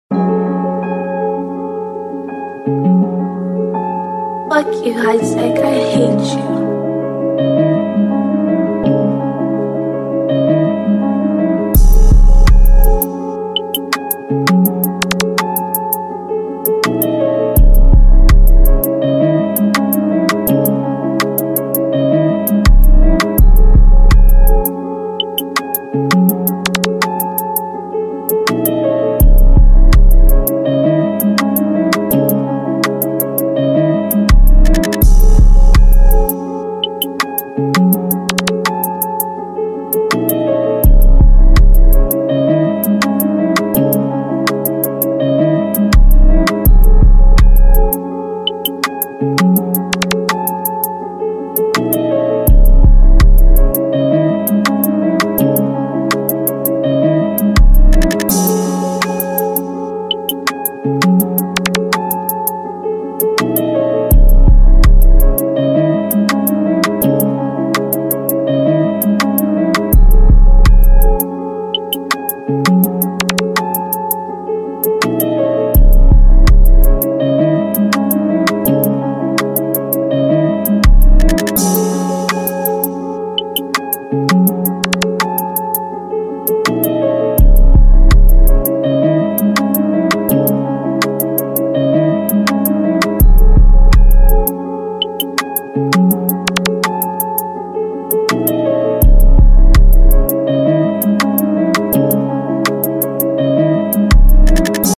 official instrumental